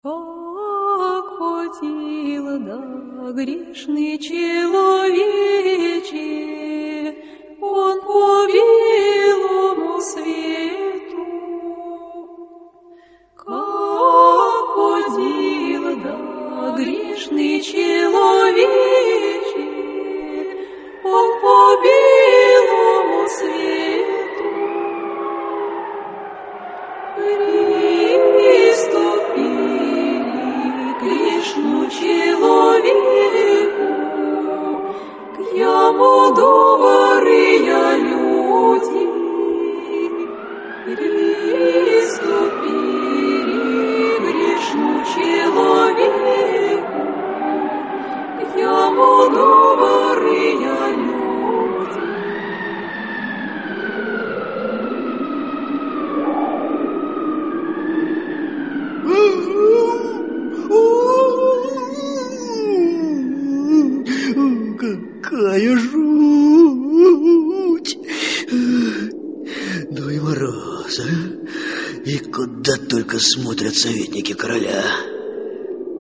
Аудиокнига Звездный мальчик (спектакль) | Библиотека аудиокниг
Aудиокнига Звездный мальчик (спектакль) Автор Оскар Уайльд Читает аудиокнигу Георгий Тараторкин.